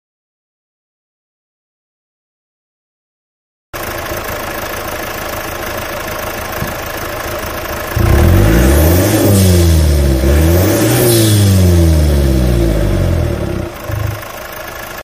Corksport ram intake on a 2.2 turbo diesel engine .